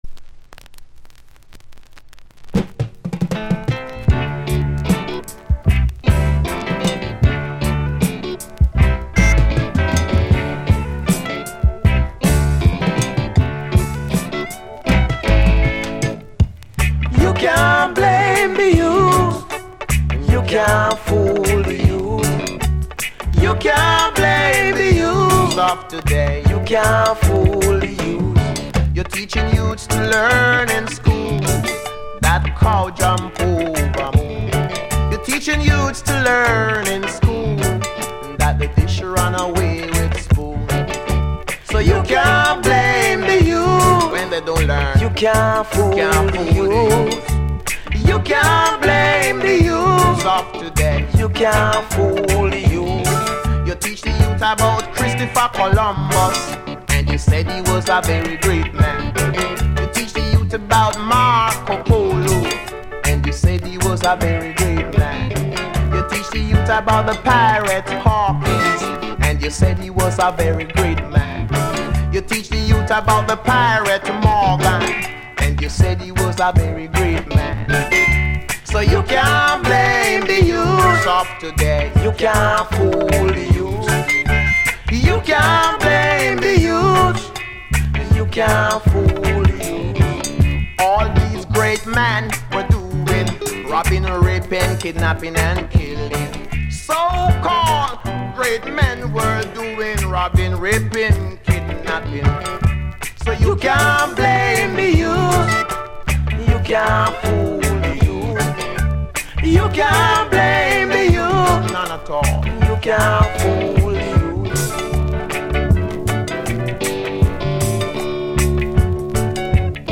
** A面の後半、傷による周期的なパチノイズあります。